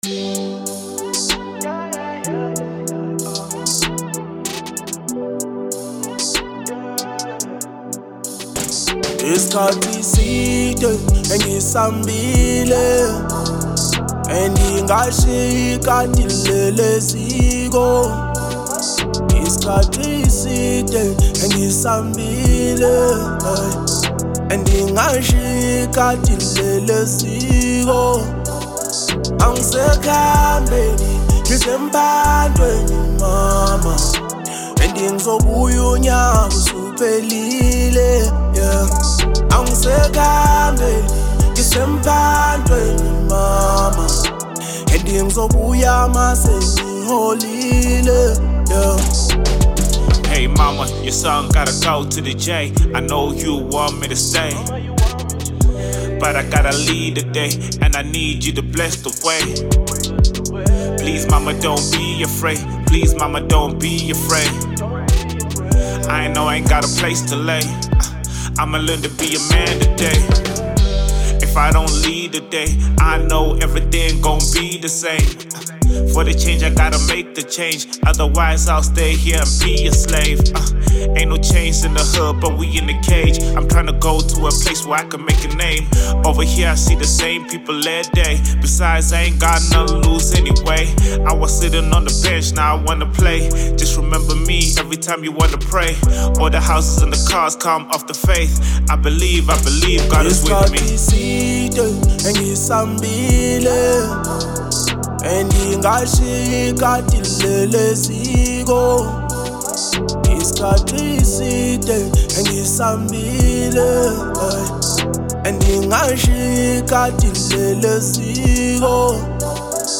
04:12 Genre : Hip Hop Size